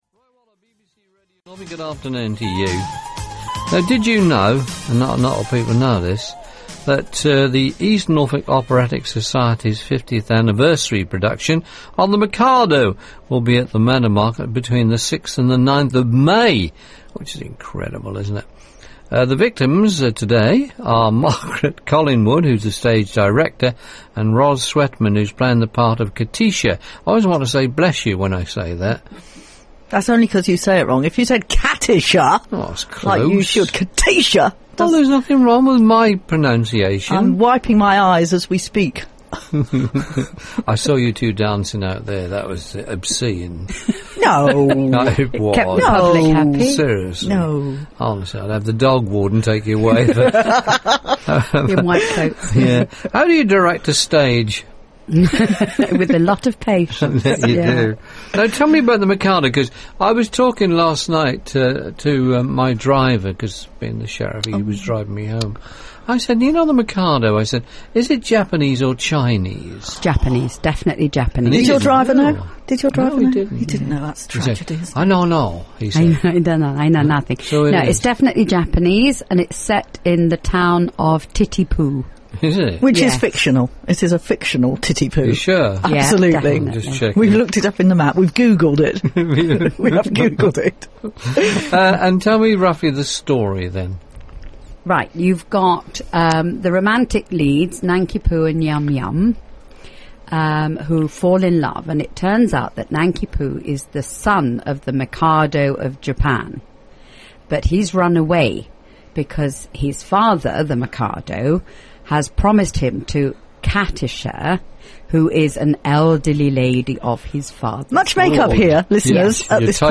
Publicity Interview broadcast on BBC Radio Norfolk on 29 April 2009